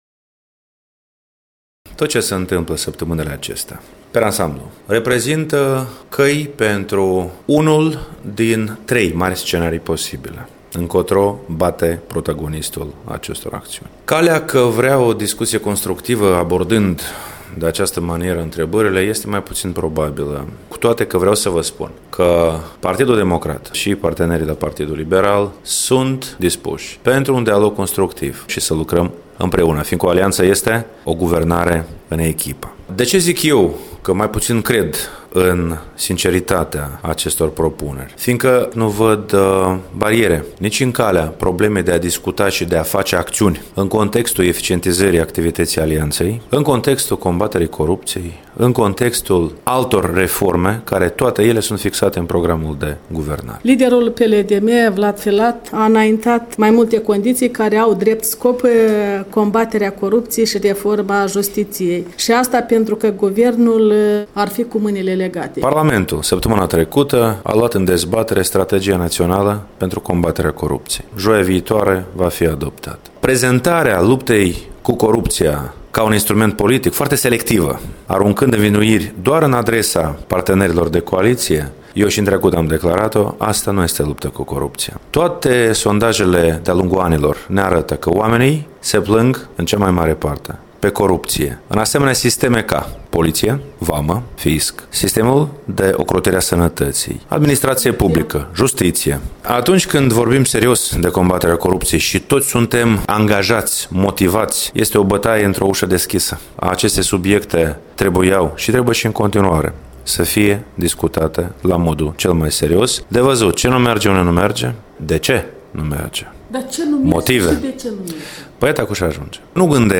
Preşedintele Partidului Democrat, Marian Lupu, preşedinte interimar şi speaker al Parlamentului, într-un interviu